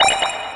pickup_key.wav